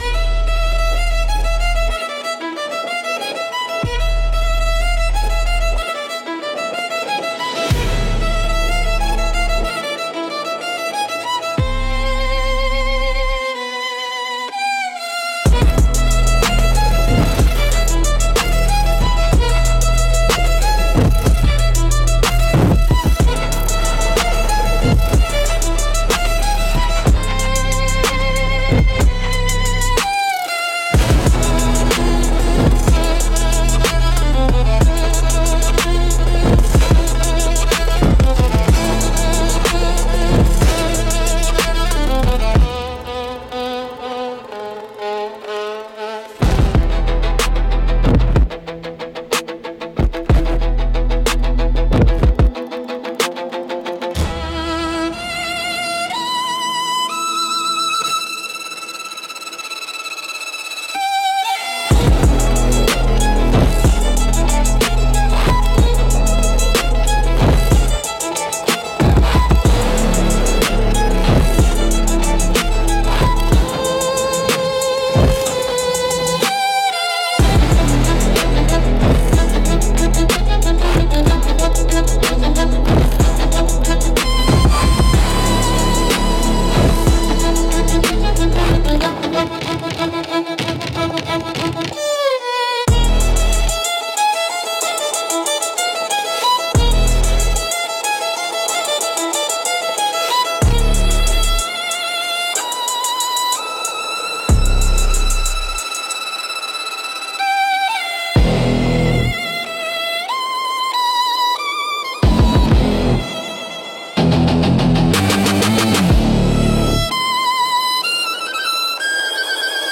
Instrumental - Dark Pop Trap x Warning Bells Bass